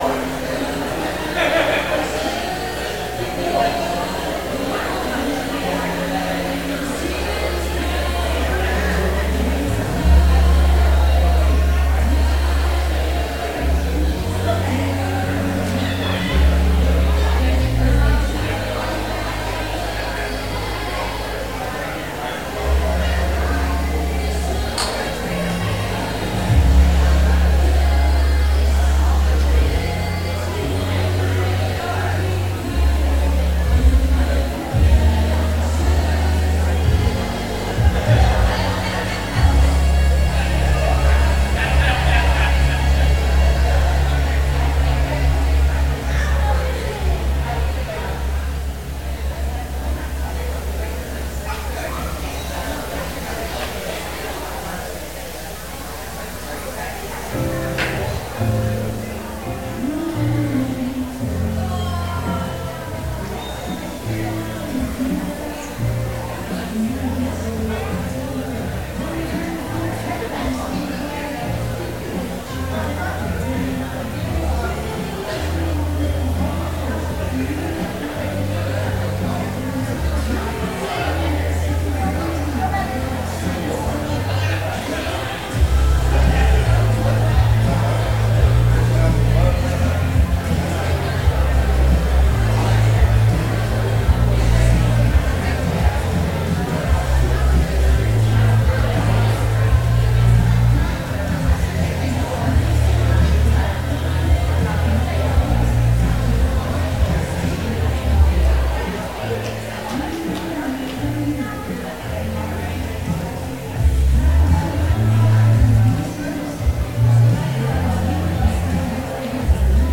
Sunday Morning Worship Red Rover Series Part 3
Red Rover...Red Rover Sermon Series